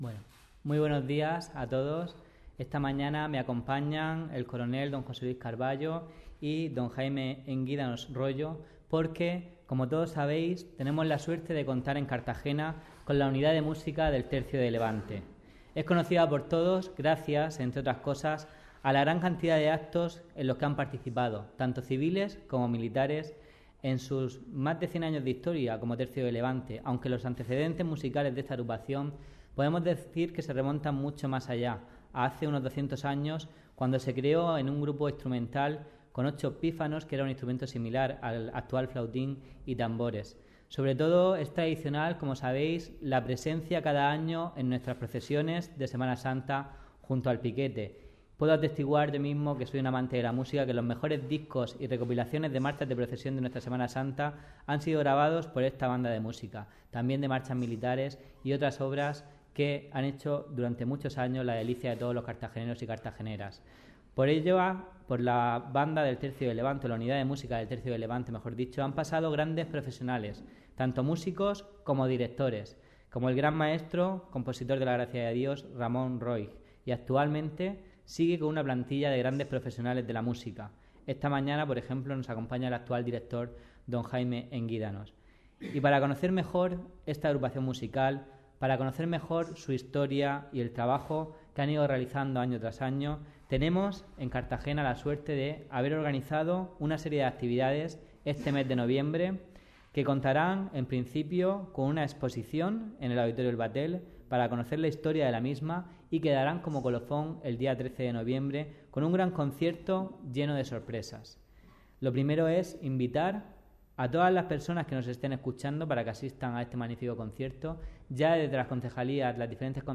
La presentación del concierto ha tenido lugar esta mañana de la mano del concejal de Cultura, David Martínez